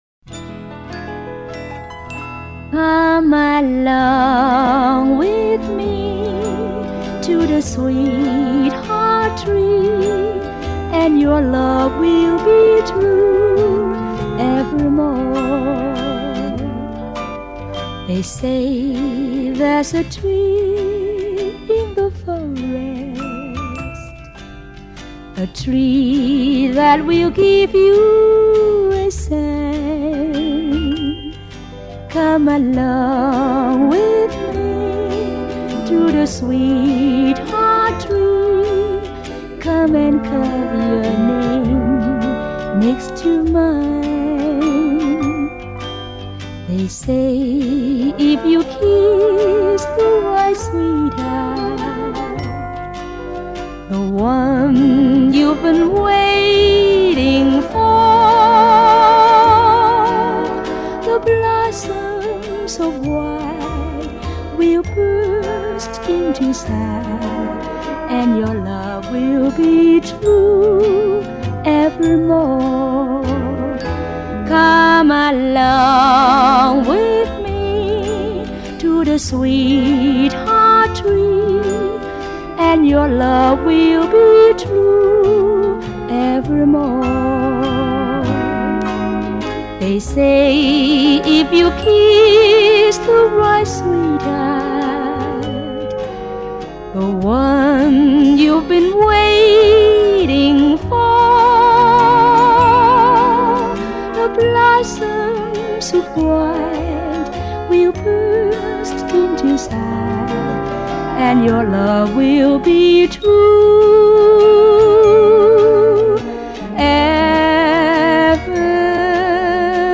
slow and haunting romantic ballads